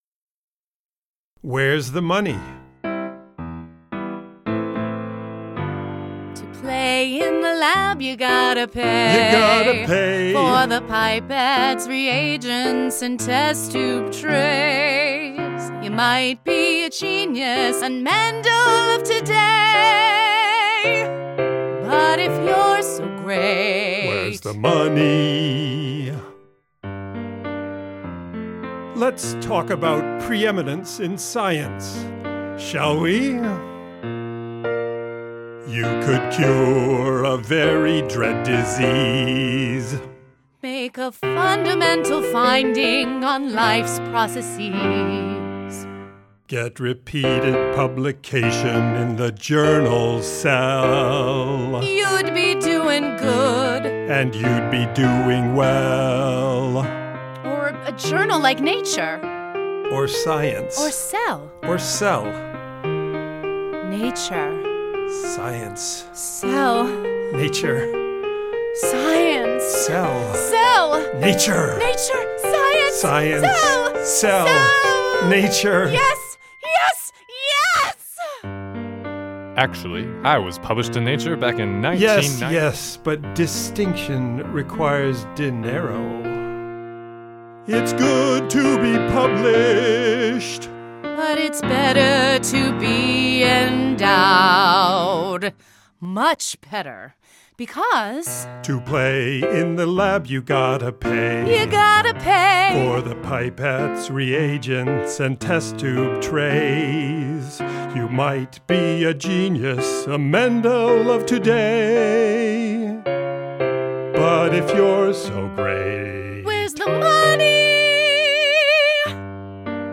About the songs: The full musical contains nineteen songs, comprising pop, rock, jazz, and rap styles.
The songs are recorded with wonderful singing actors, but generally canned piano exported from my notation software.